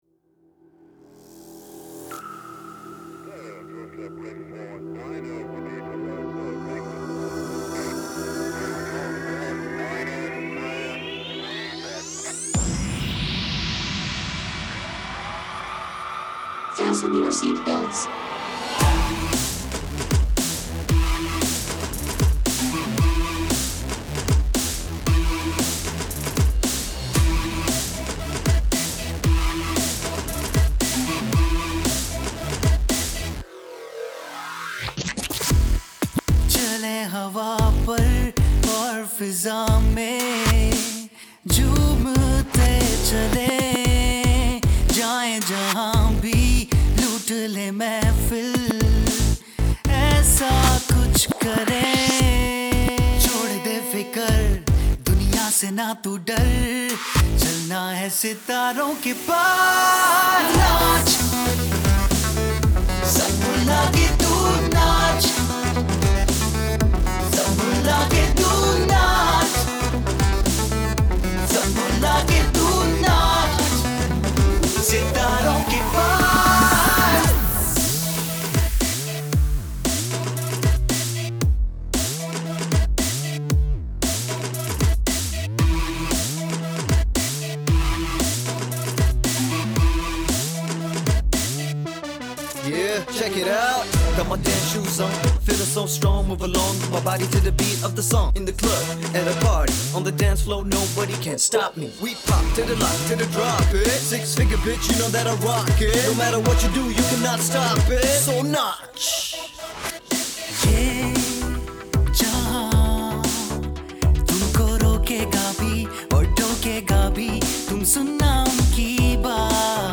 Official Sound Track
totally groovy track